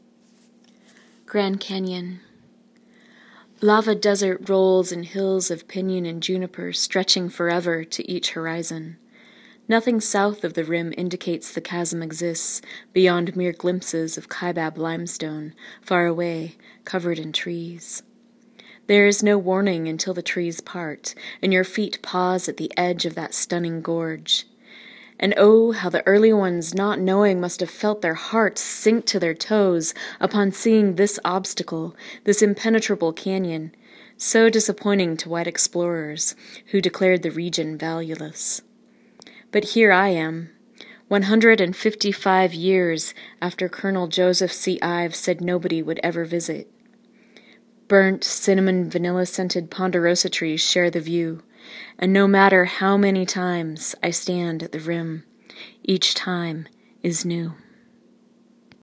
listen to her read